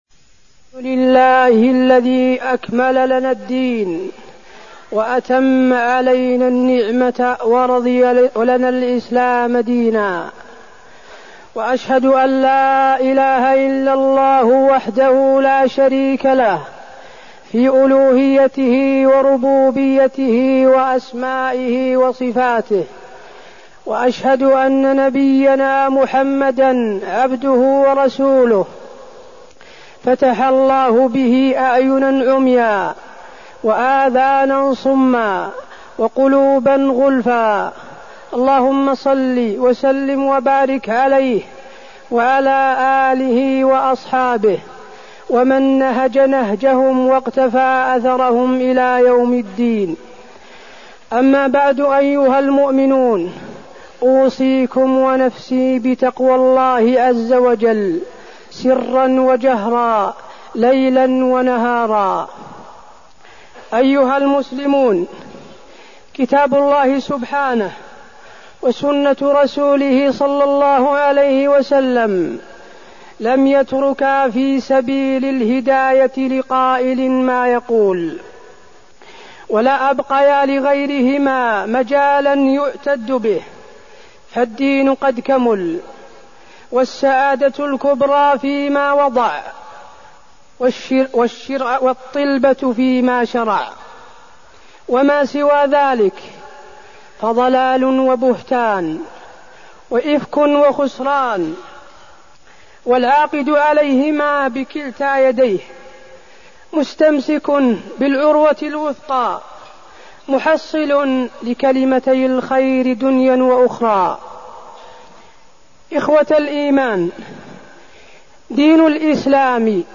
تاريخ النشر ٣ رجب ١٤١٩ هـ المكان: المسجد النبوي الشيخ: فضيلة الشيخ د. حسين بن عبدالعزيز آل الشيخ فضيلة الشيخ د. حسين بن عبدالعزيز آل الشيخ السنة والبدعة